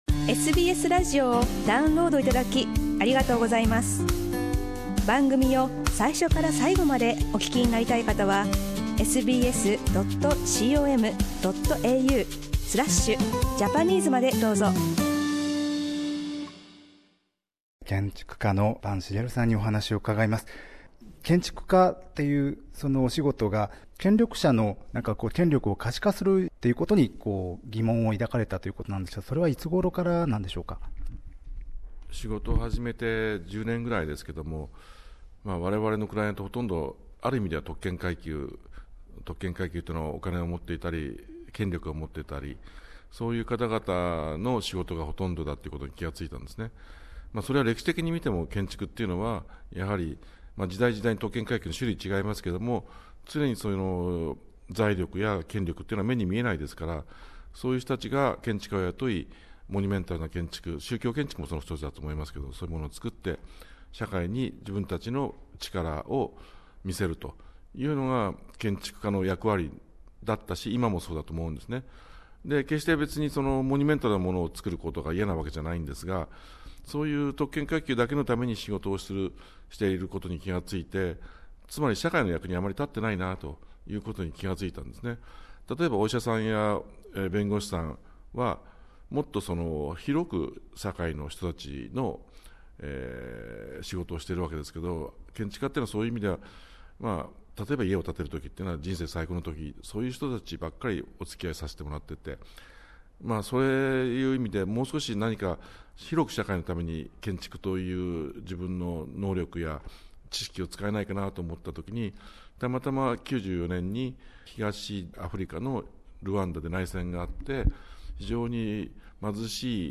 世界中で人道的支援を続ける坂さんの精力的な活動について、お話を伺いました。